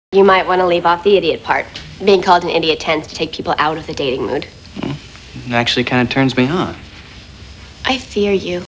These wavs have been recorded by me and are of a high quality.